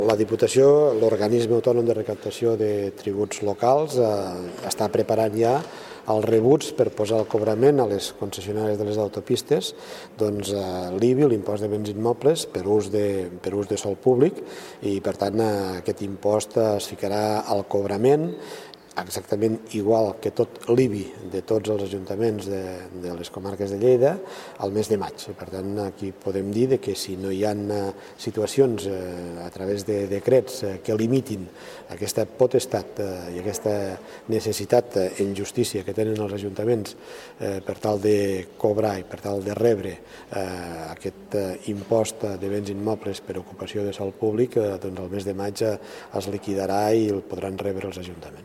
El president de la Diputació de Lleida, Joan Reñé, explica que el pròxim mes de maig l’Organisme Autònom de Gestió i Recaptació de Tributs Locals (OAGRTL) remetrà a les concessionàries el rebut corresponent a l’IBI amb el 100% de l’import.